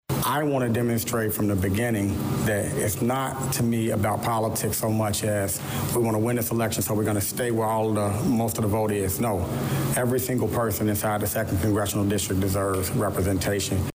Candidate for Congress, State Senator Willie Preston, speaks with reporters during a Wednesday morning stop in Danville at the Roselawn Fitness Center.